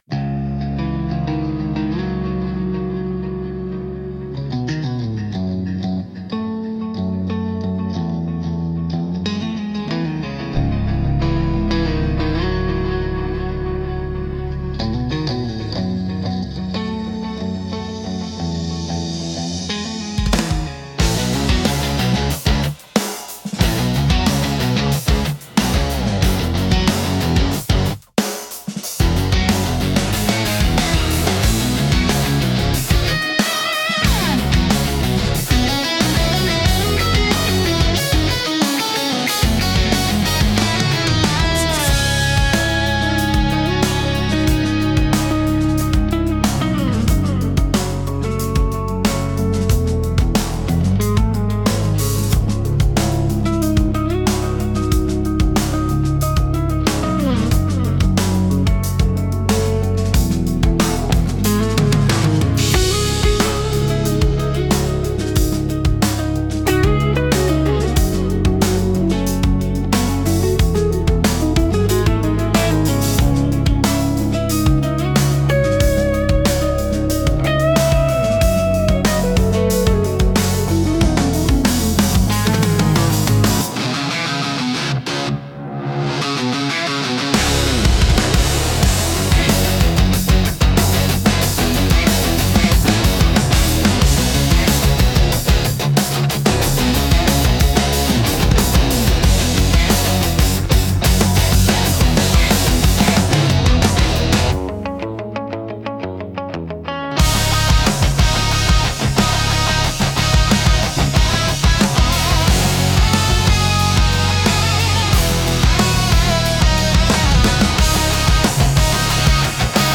Instrumental - Stories of Wire and Wood